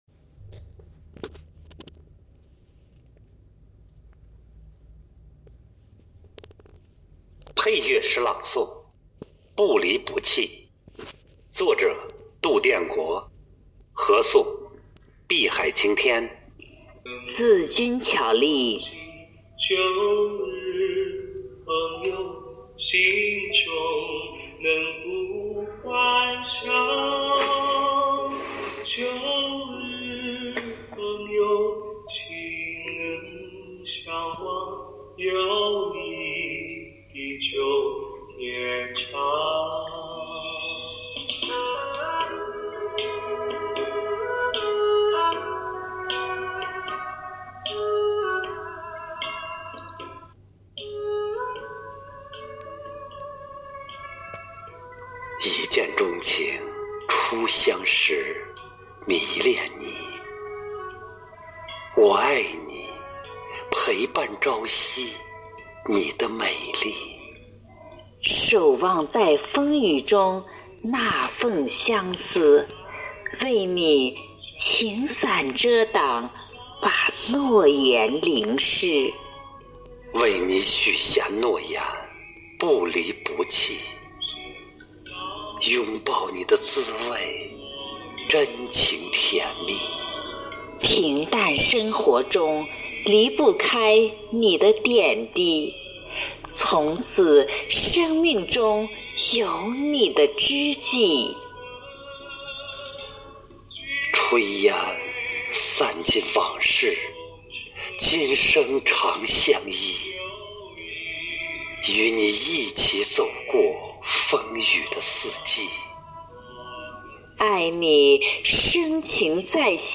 喜欢尝试不同题材不同主题的作品，追求不同语境下不同的表达方式，或低回婉转，娓娓道来，或大气磅礴，荡气回肠，力求声情并茂打动听者心灵。